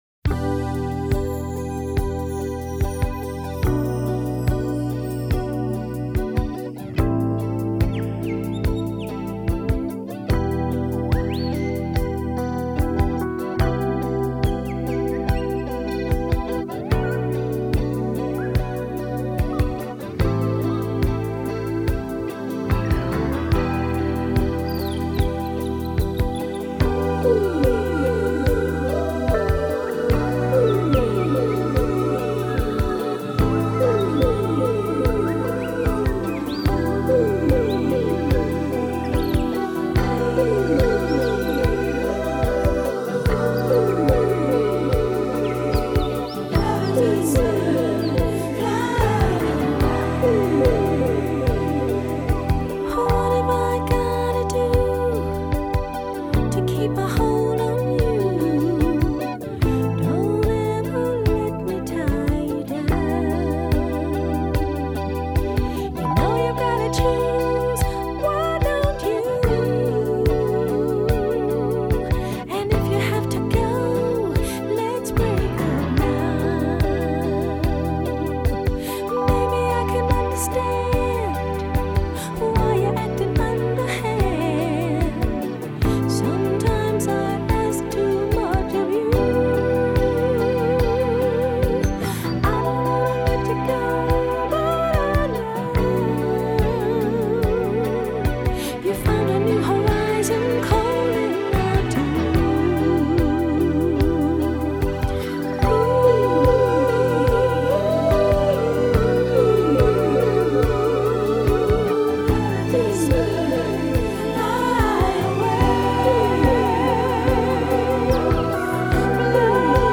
1. КОМПОЗИЦИЯ С ВОКАЛОМ